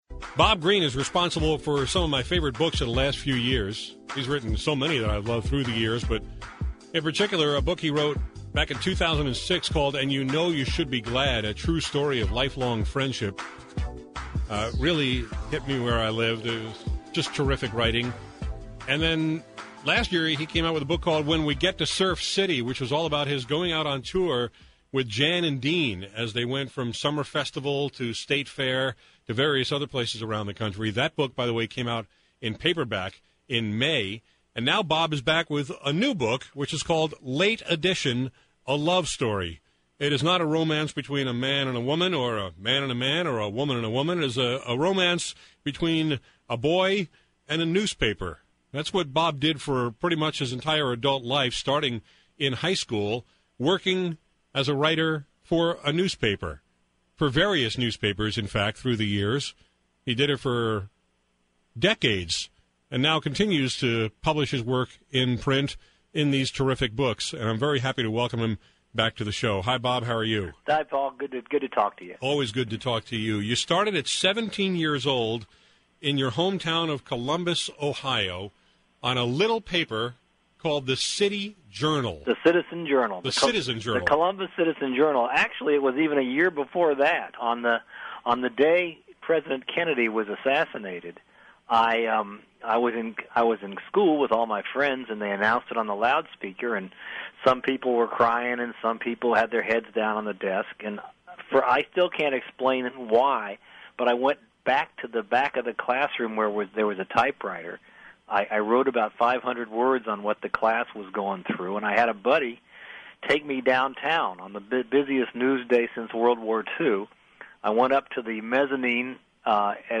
When we talked about it recently on KTRS/St. Louis, Bob regaled me with how he wrote his first newspaper piece as a high school student on the day JFK was assassinated.